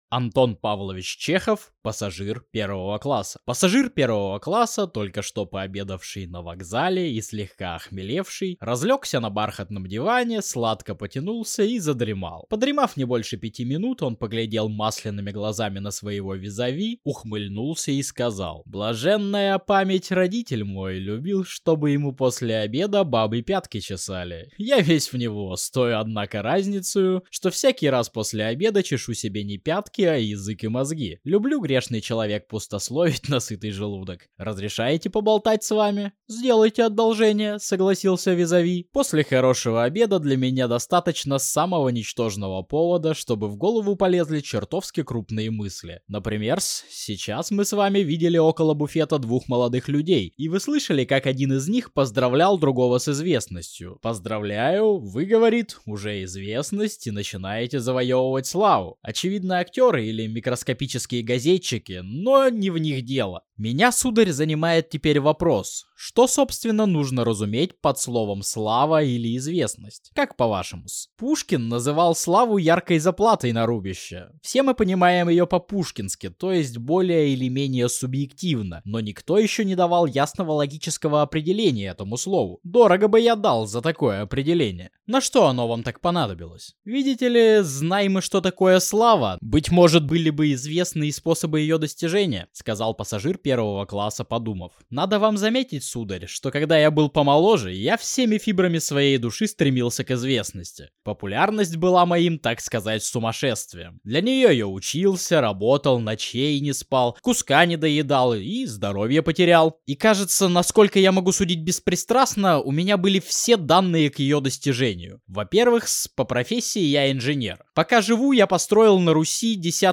Аудиокнига Пассажир 1-го класса | Библиотека аудиокниг